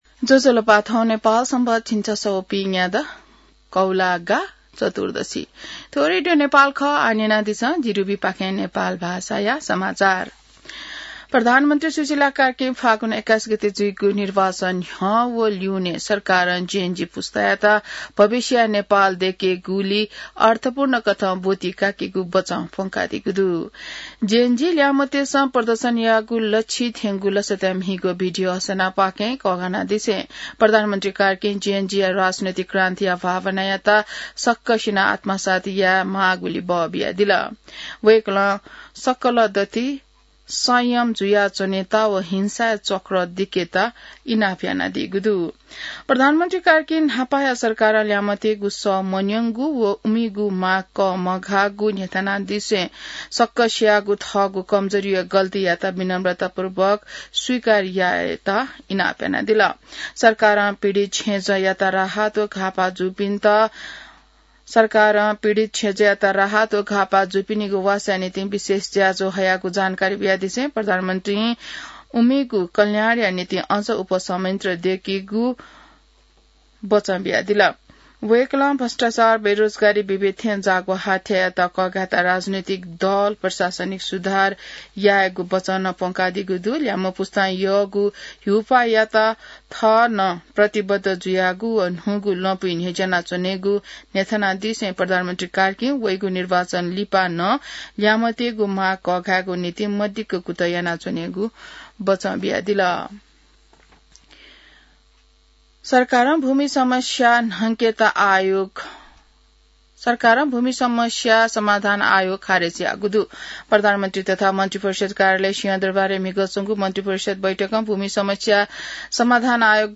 नेपाल भाषामा समाचार : २४ असोज , २०८२